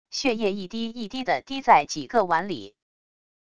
血液一滴一滴的滴在几个碗里wav音频